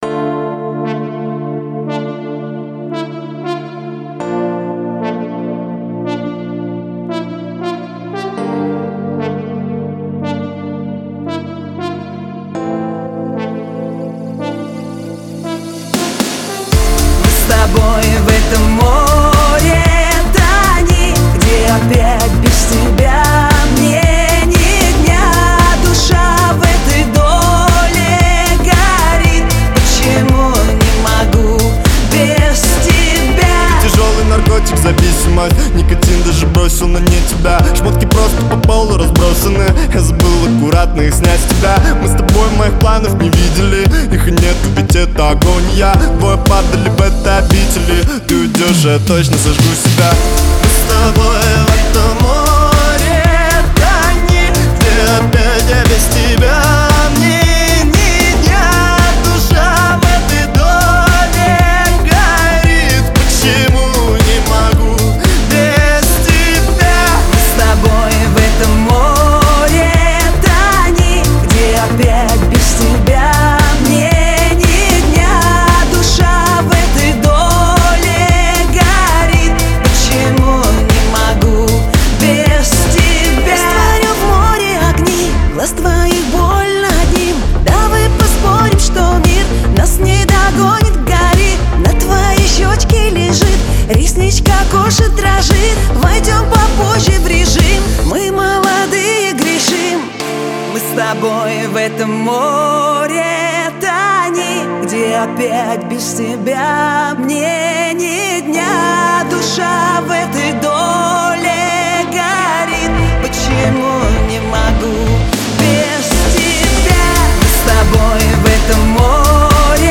русские песни , танцевальные песни Размер файла